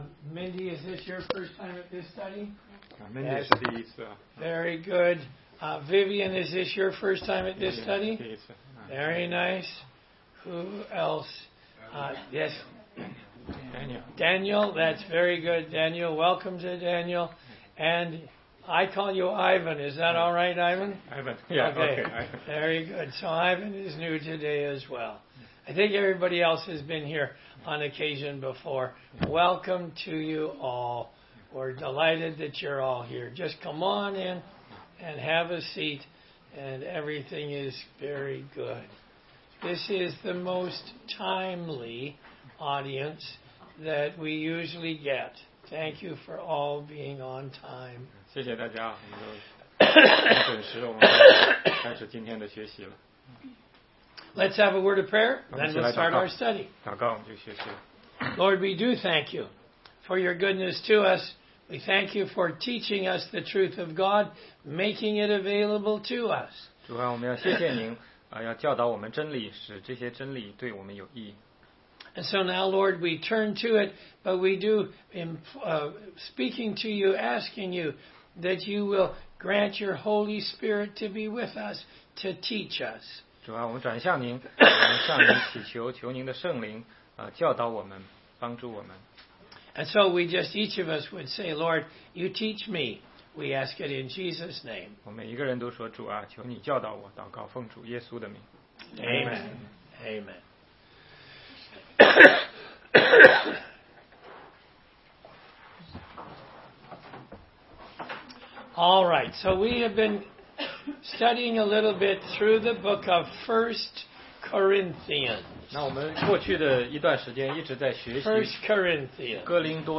16街讲道录音 - 哥林多前书6章1-11节：如何处理弟兄间的纷争